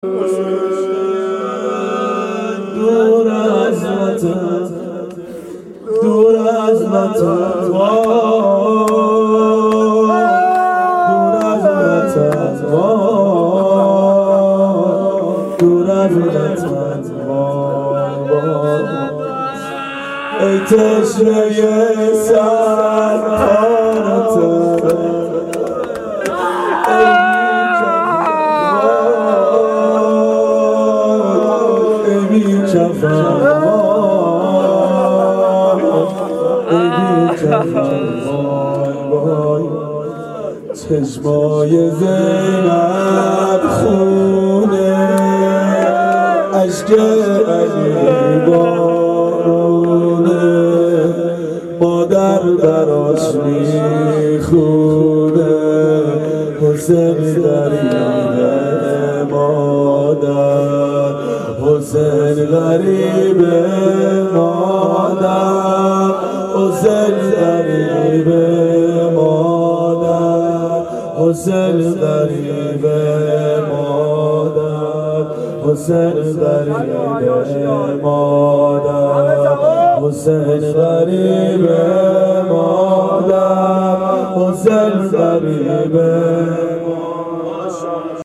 شب عاشورا 92 هیأت عاشقان اباالفضل علیه السلام منارجنبان